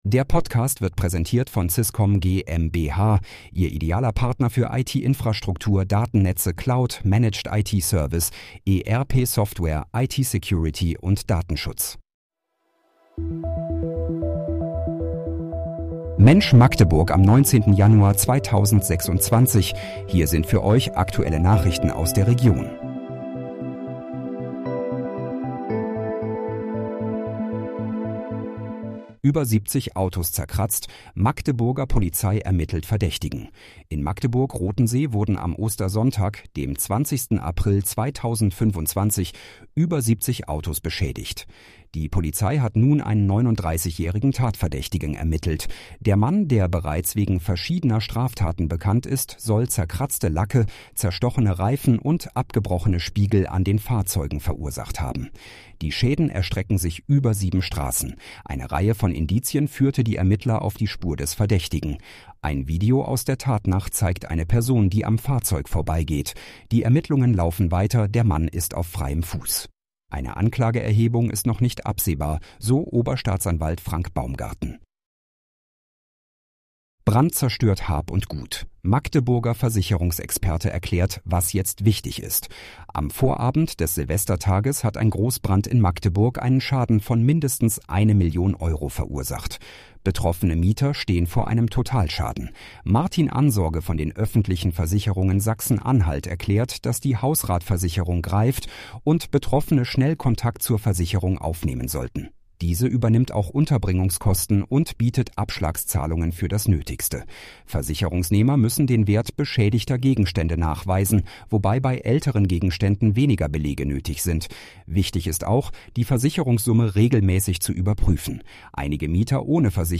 Mensch, Magdeburg: Aktuelle Nachrichten vom 19.01.2026, erstellt mit KI-Unterstützung